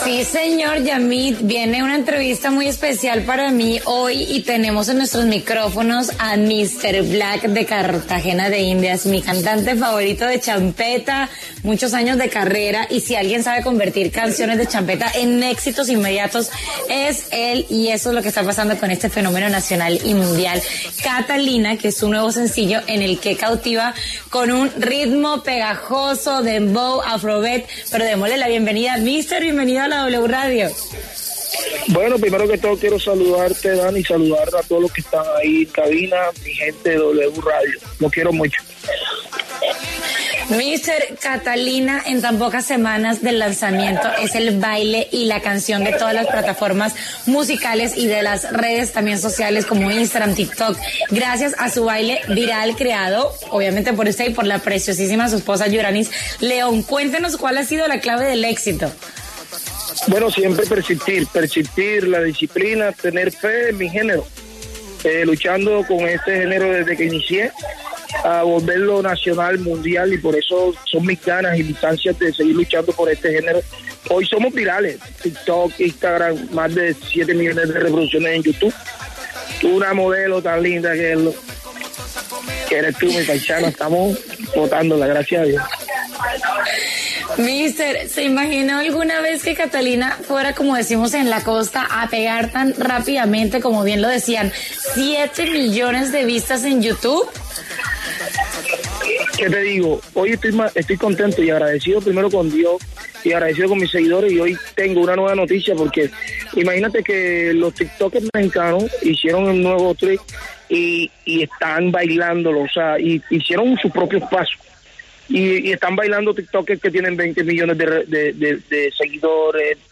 El cantante colombiano habló con La Hora del Regreso de este lanzamiento que lo ha catapultado en la cima de las tendencias en redes sociales con su coreografía y su ritmo.